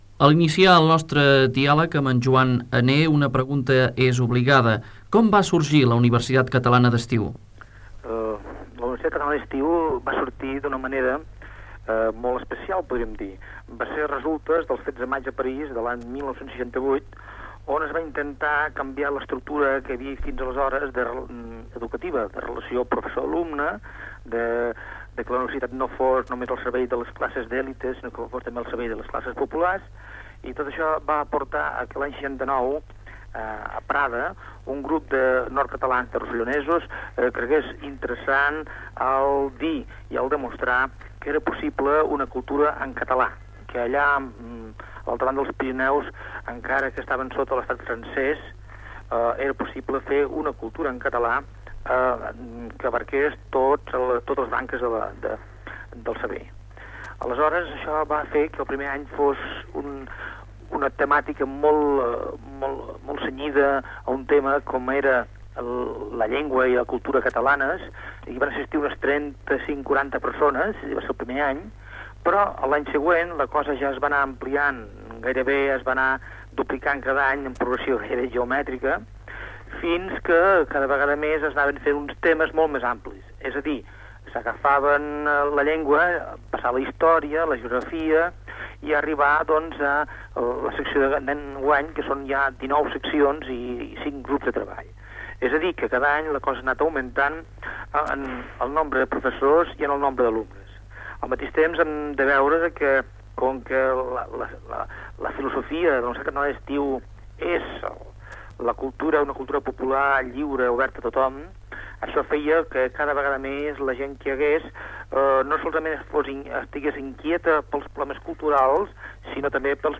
Entrevista
Informatiu